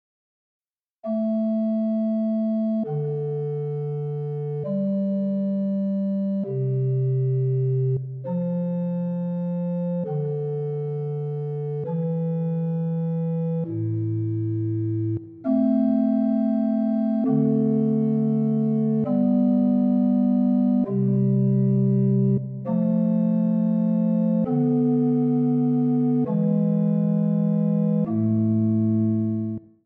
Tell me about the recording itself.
The .mp3 recording is on organ, at 8' pitch.